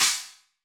Closed Hats
TC3Hat13.wav